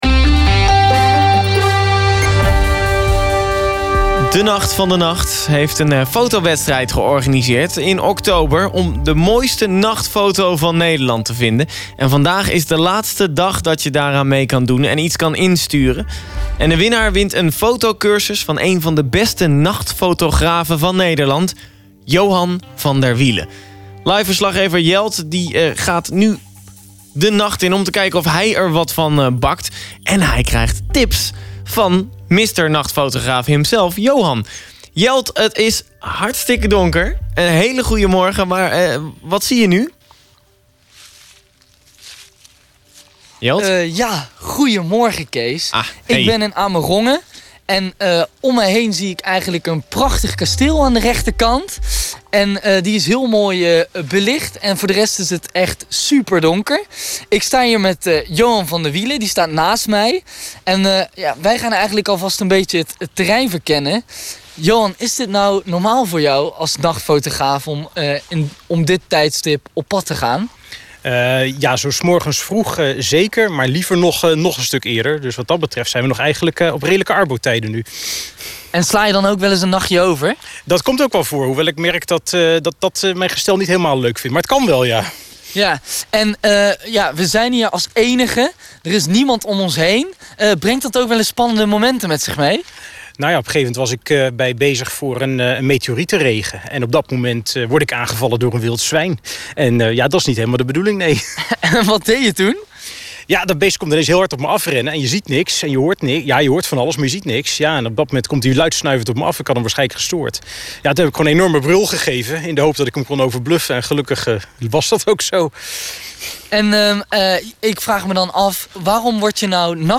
Kasteel Amerongen bij nacht